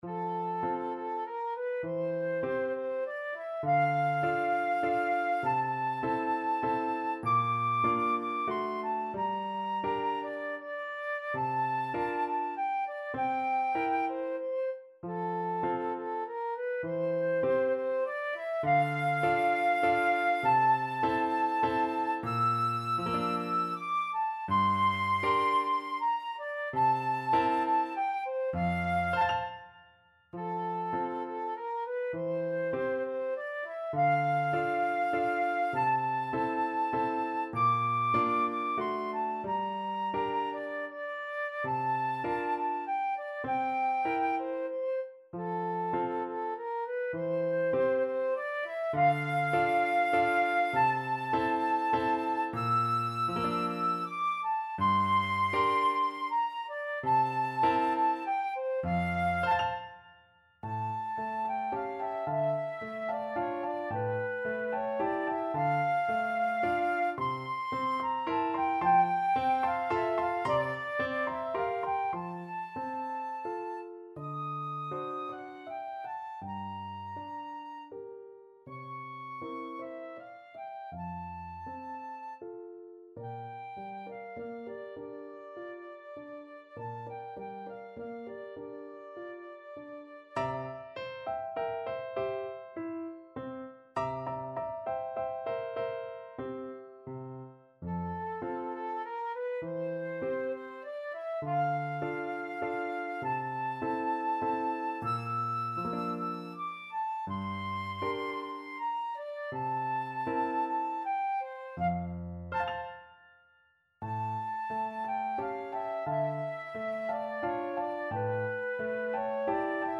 Flute
F major (Sounding Pitch) (View more F major Music for Flute )
~ = 100 Tranquillamente
3/4 (View more 3/4 Music)
Classical (View more Classical Flute Music)